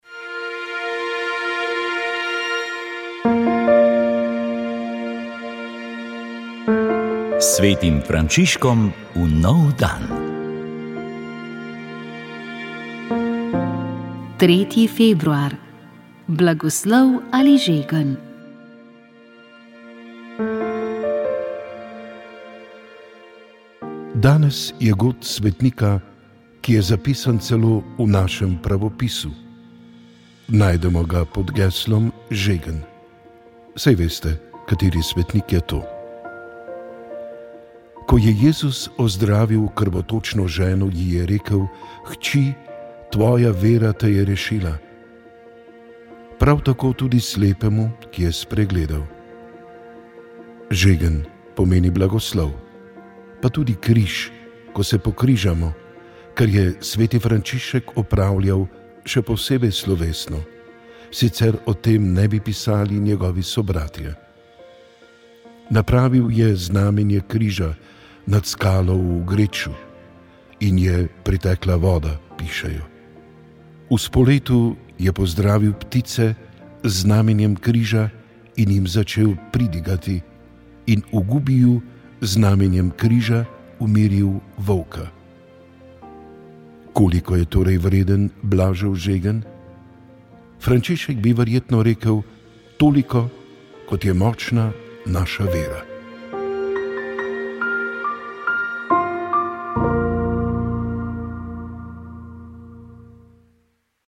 Vremenska napoved 21. maj 2022